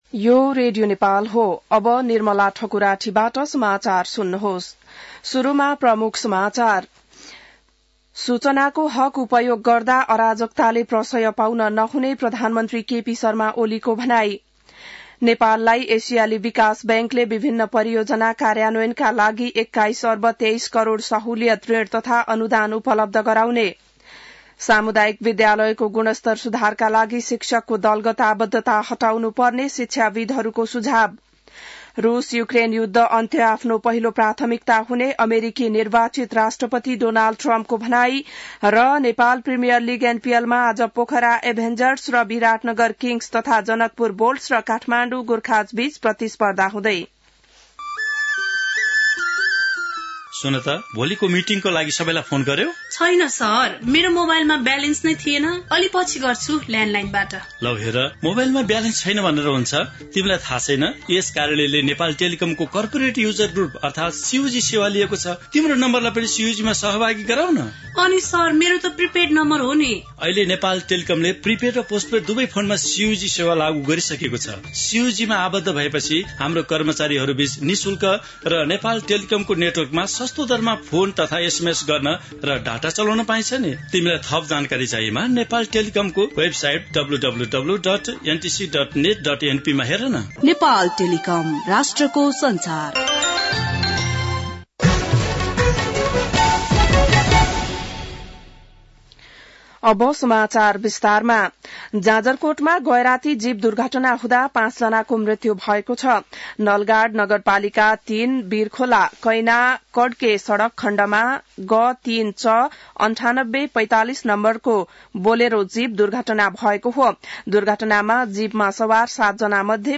बिहान ७ बजेको नेपाली समाचार : २८ मंसिर , २०८१